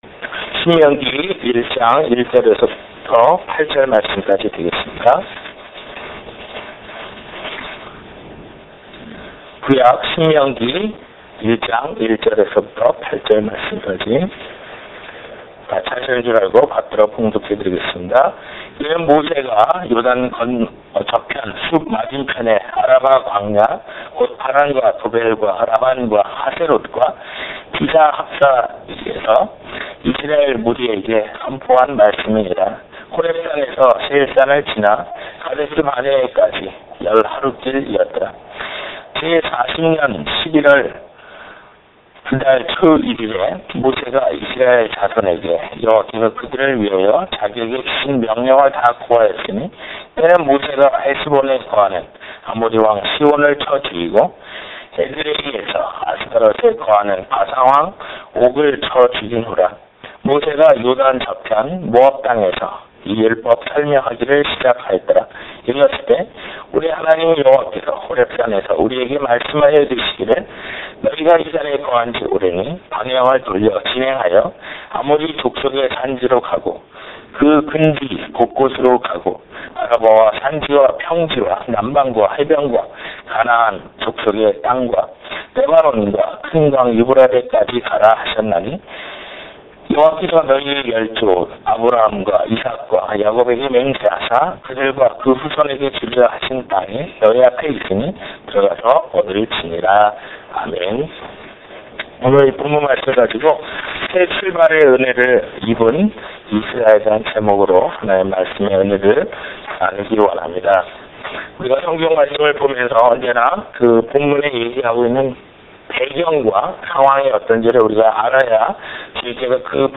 빅토빌예수마음교회 주일설교파일 - 주일설교 9월 10일